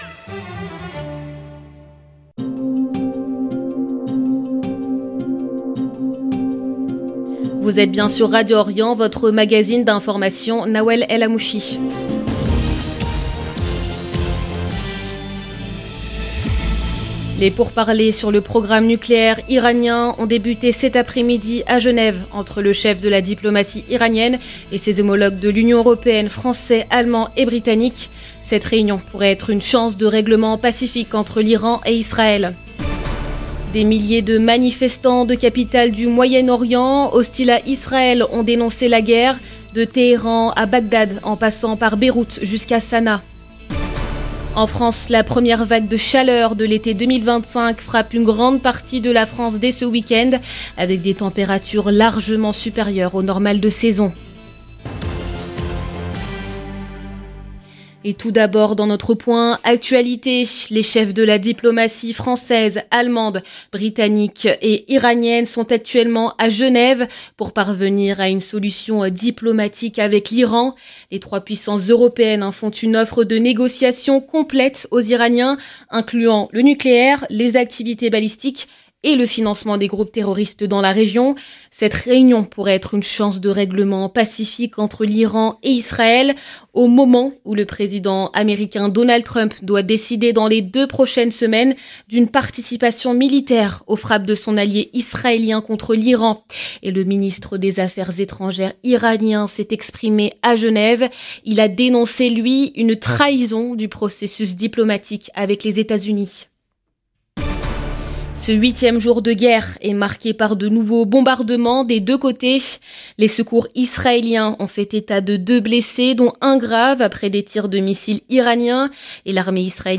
Magazine de l'information de 17H00 du vendredi 20 juin 2025